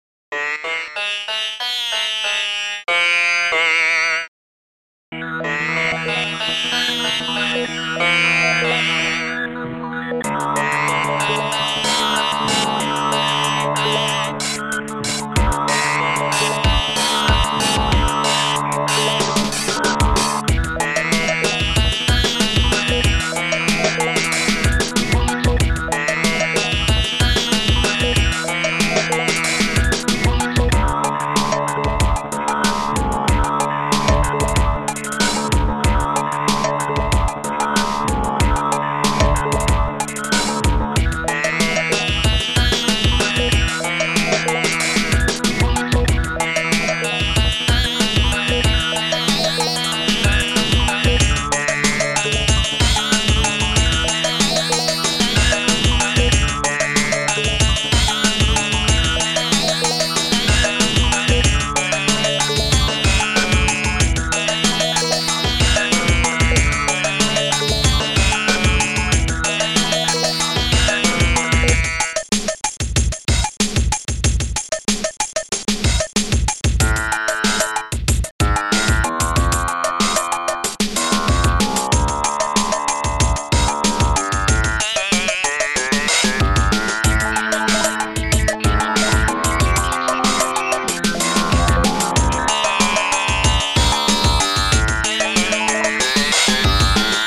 ST-11:sitar
ST-13:kraftwerksnare
ST-13:Tambo1
ST-06:HighHat5